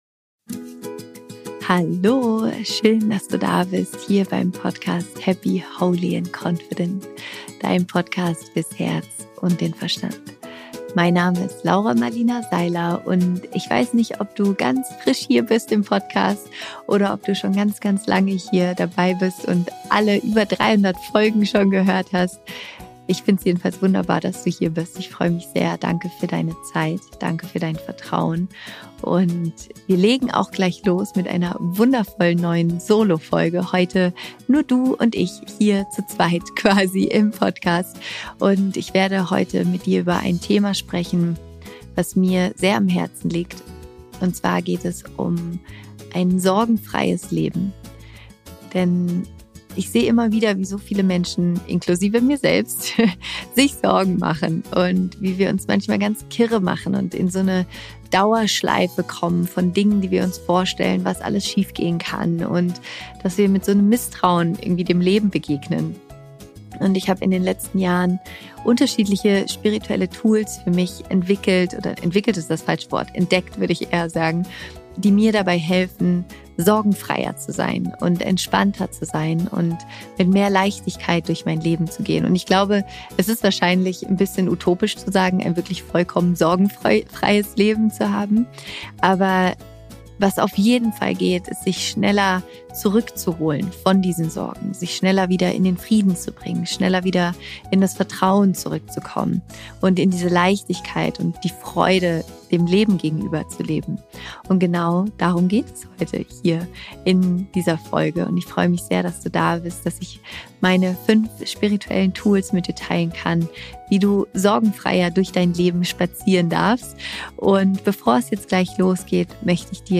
Am Ende dieser Folge machen wir eine gemeinsame kurze Meditation in Verbindung mit einer Visualisierung, die dir dabei helfen kann, sorgenfreier durch dein Leben zu gehen.